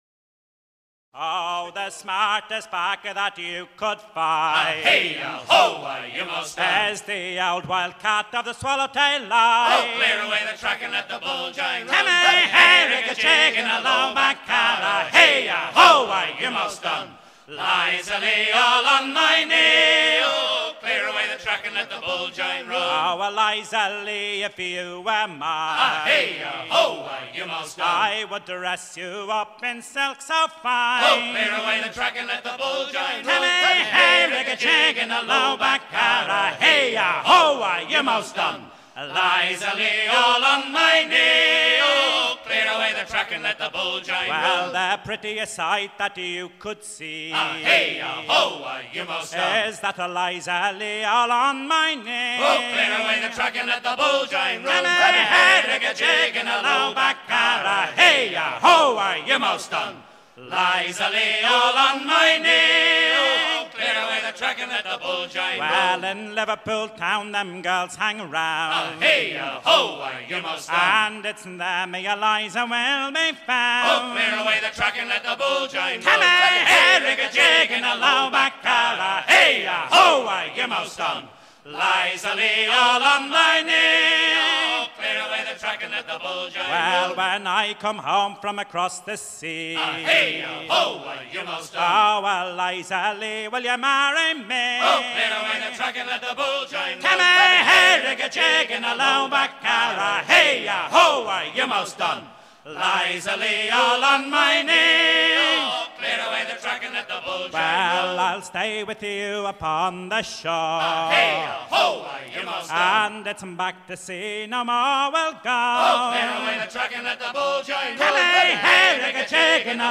Clear away the track Détails de l'archive Titre Clear away the track Origine du titre : Editeur Note chant apprécié des équipages des paquebots à voiles américains.
à virer au cabestan
Pièce musicale éditée